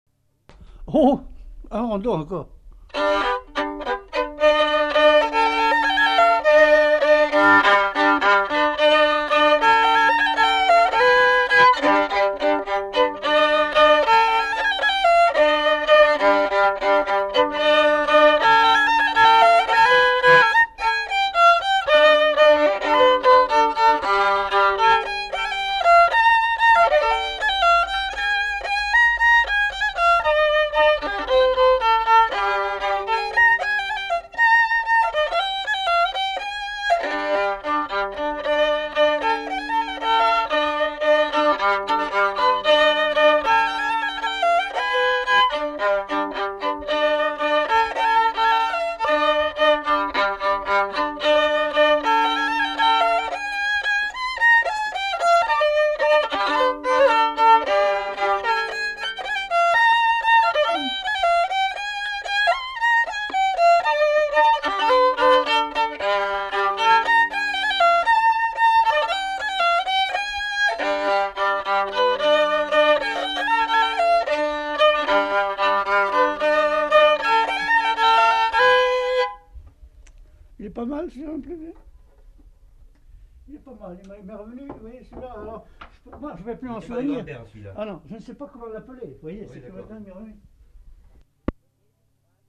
Aire culturelle : Haut-Agenais
Lieu : Castillonnès
Genre : morceau instrumental
Instrument de musique : violon
Danse : rondeau